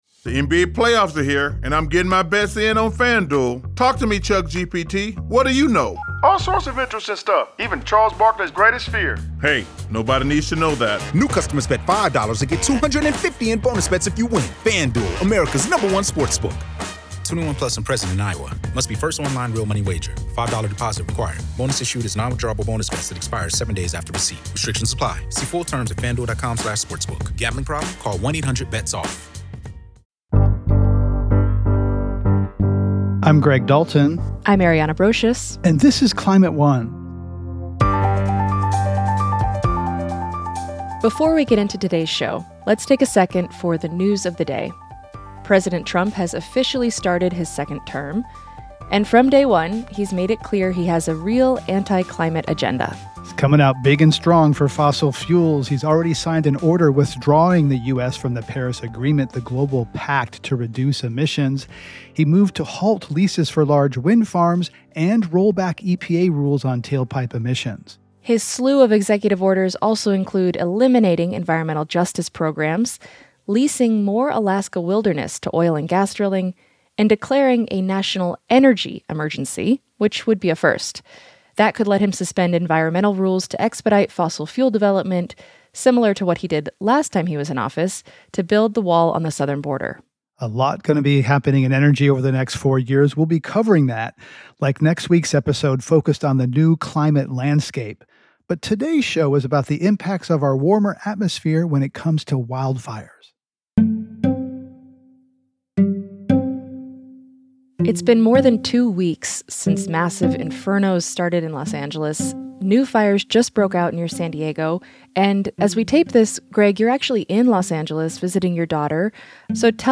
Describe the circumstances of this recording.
California Fish & Game Commission This program was recorded in front of a live audience at the Commonwealth Club of California on June 14, 2016